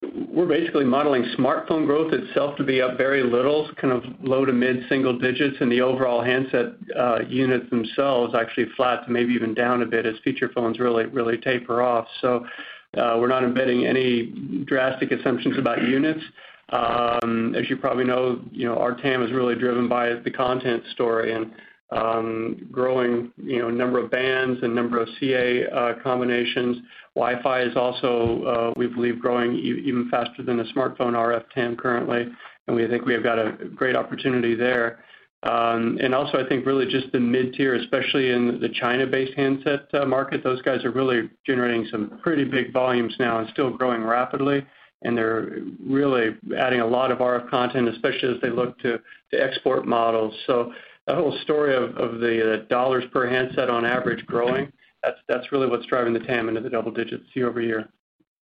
He was responding to a question from a financial analyst during the fiscal Q3 2017 earnings call on February 1, 2017.